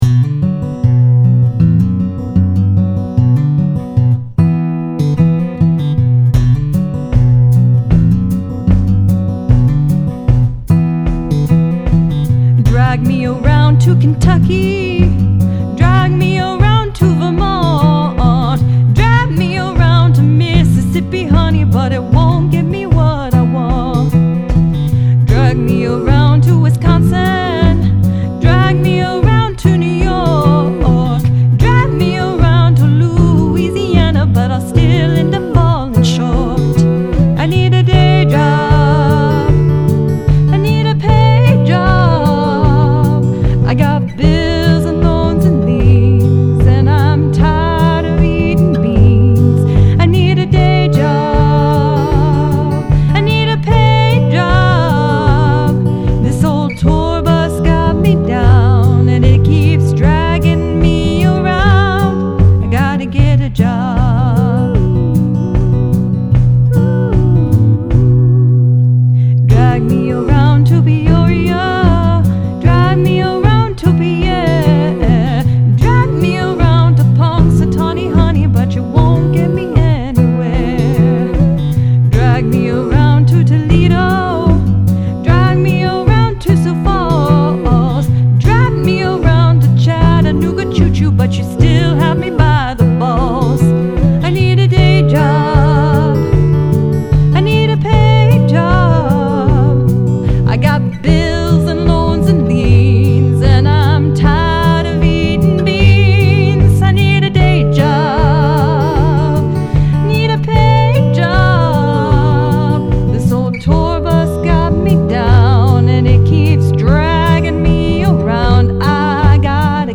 I really like this old-timey style of songwriting.
It starts off okay, then wanders sharp.